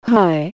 Gemafreie Voices